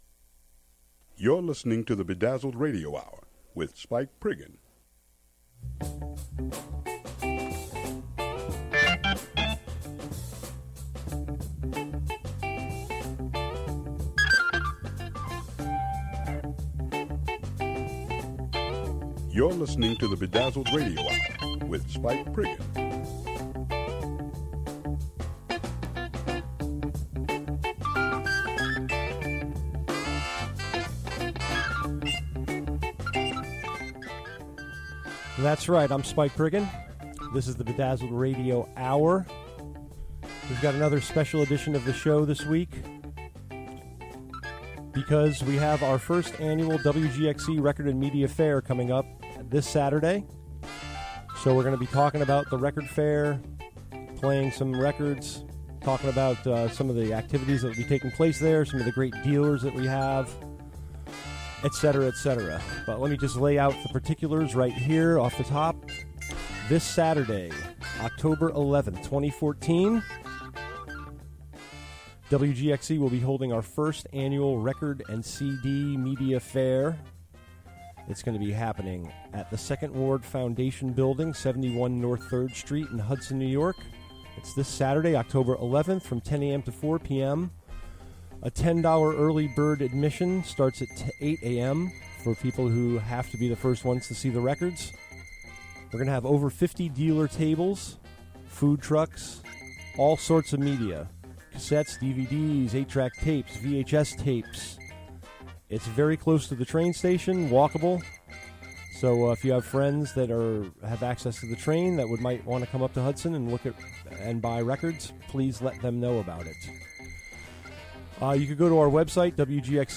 Live from the Hudson studio